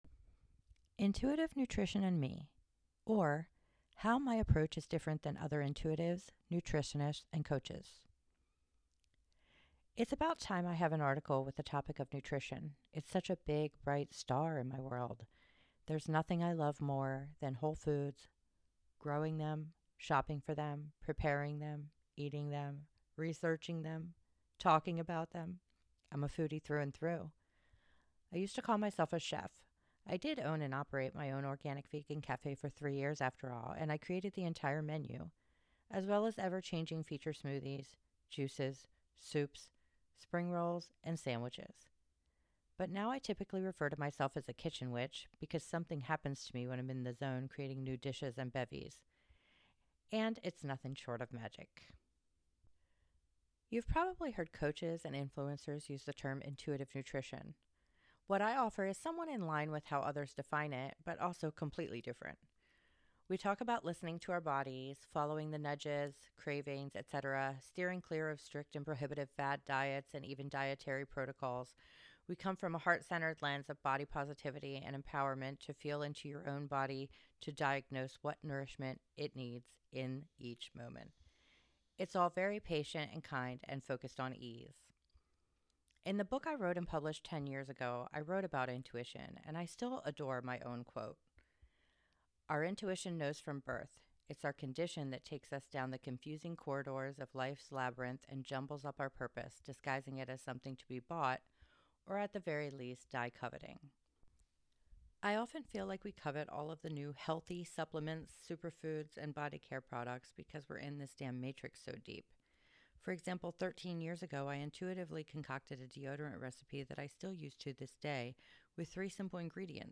Click below for audio of this blog post read by me!